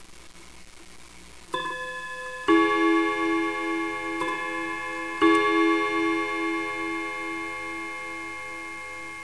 Mauthe German Mantle Clock This one was just too nice to pass up.
This clock features a lovely Bim-Bam strike on 3 rods.